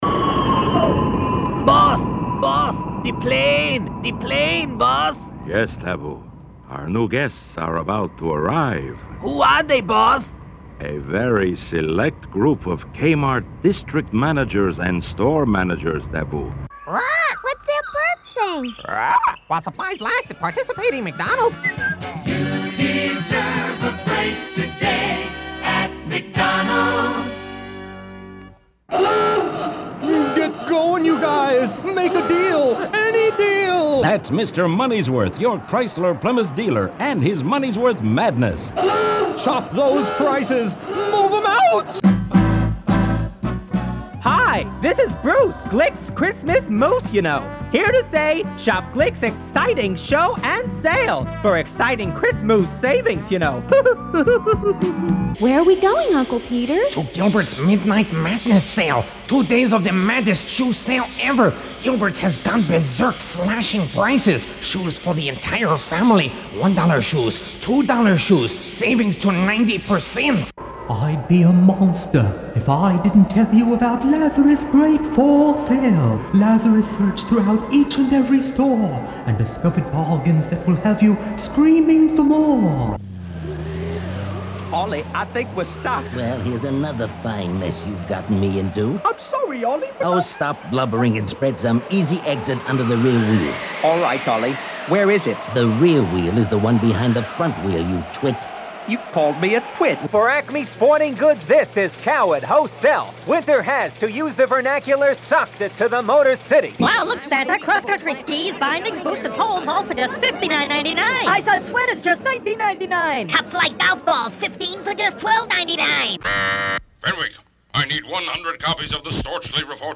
Here’s a brief montage of snippets.
I was a parrot for McDonald’s and a crazed auto dealer for Chrysler